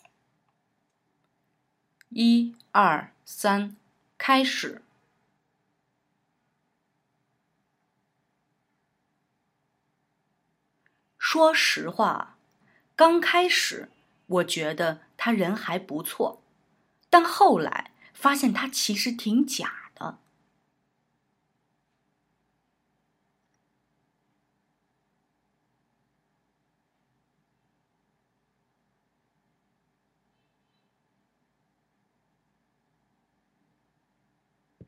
Höre dir Audio 3 an, in dem nur Part B eingesprochen wurde, und übernimm diesmal Part A! Damit du weißt, wann du einsetzen musst, gibt es vorab ein Startsignal.
Übung 3: Sprich Part A!